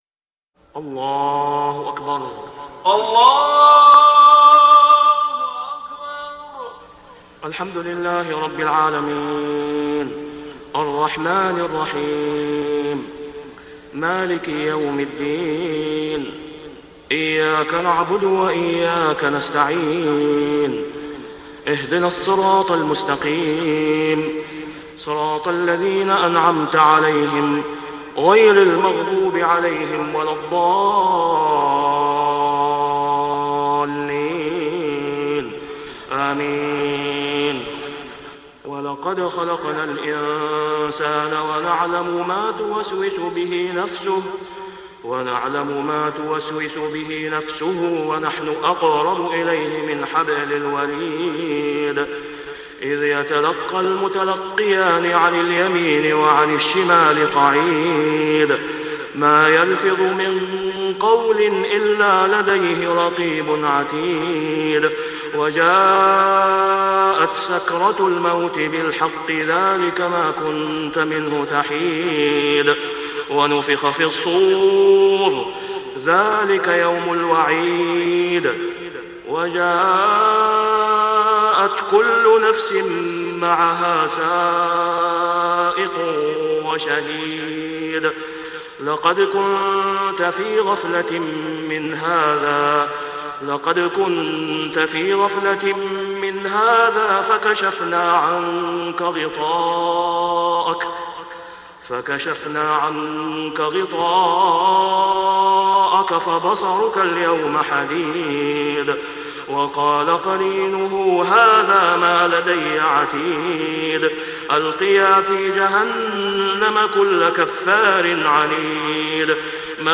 ( صلاة الفجر و العام غير معروف ) | سورة قٓ 16-45 > 1423 🕋 > الفروض - تلاوات الحرمين